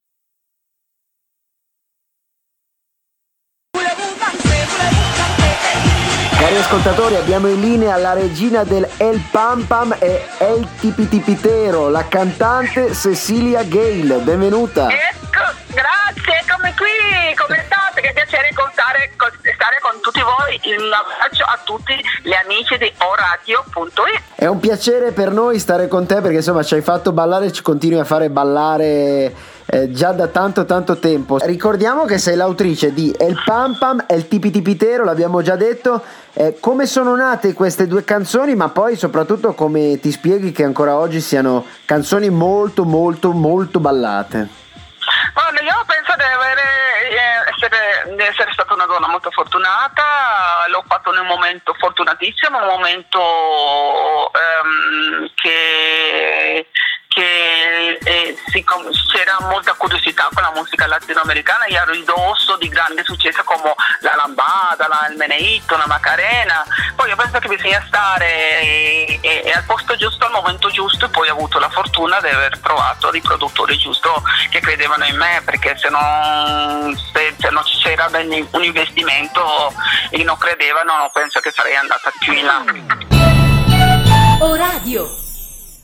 Intervista-alla-cantante-Cecile-Gayle-Estratto.mp3